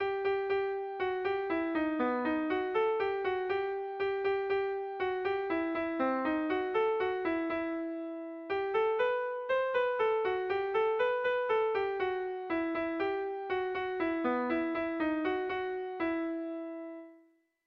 Kontakizunezkoa
Zortzikoa, txikiaren moldekoa, 4 puntuz (hg) / Lau puntukoa, txikiaren modekoa (ip)
AABA